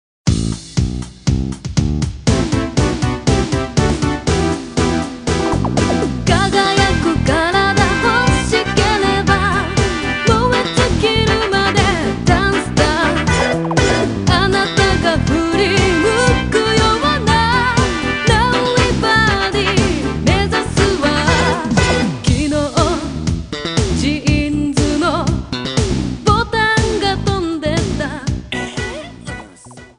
Trimmed and fade-out
Fair use music sample